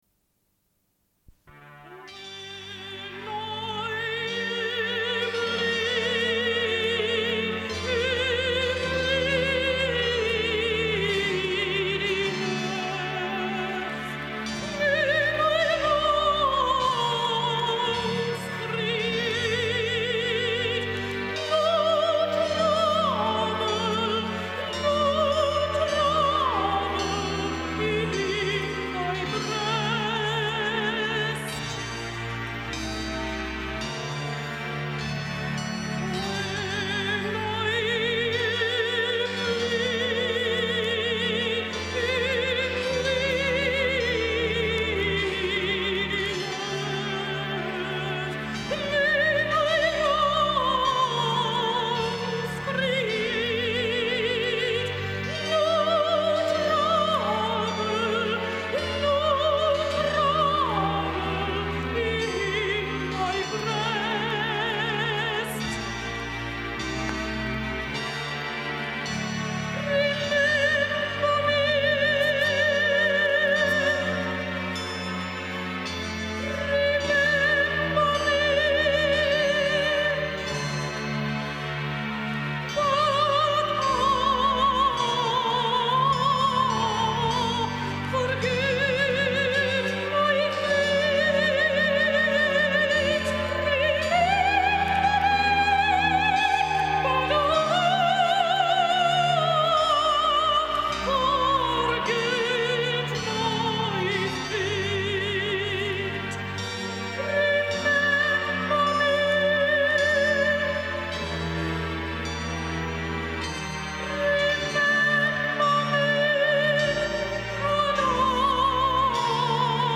Une cassette audio, face A31:10